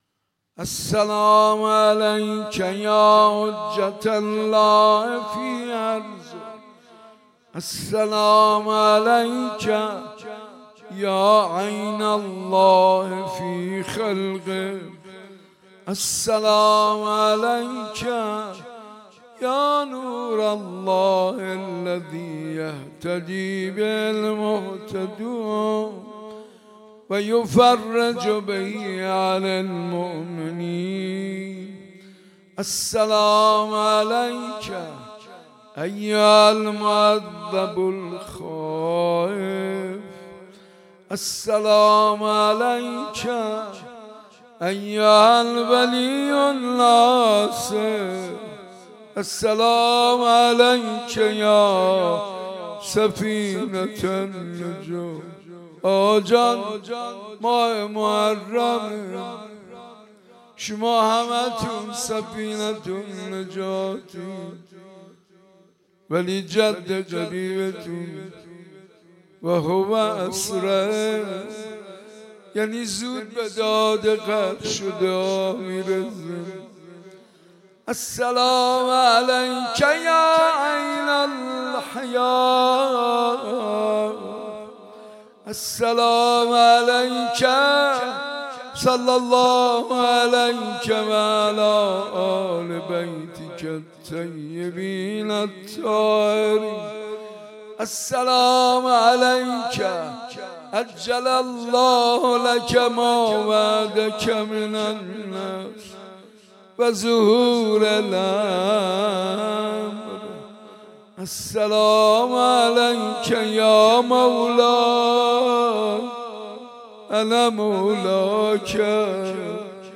مداحی شب اول محرم 1399 با نوای حاج منصور ارضی